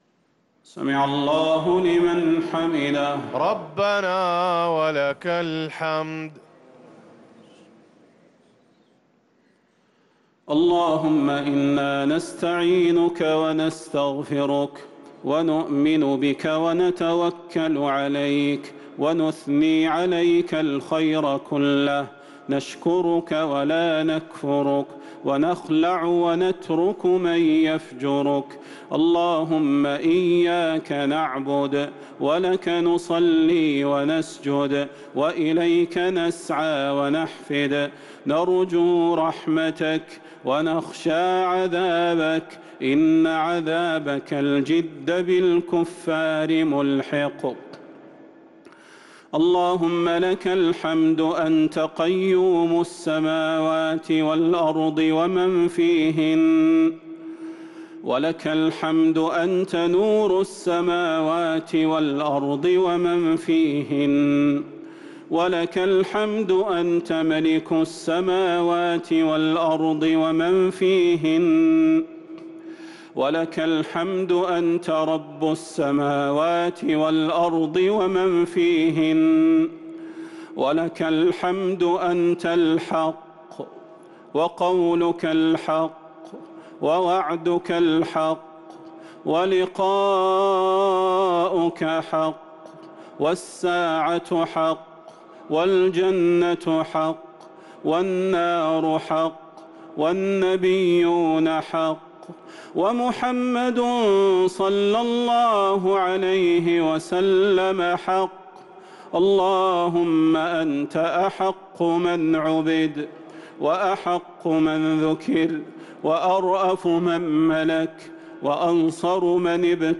دعاء القنوت ليلة 22 رمضان 1444هـ | Dua for the night of 22 Ramadan 1444H > تراويح الحرم النبوي عام 1444 🕌 > التراويح - تلاوات الحرمين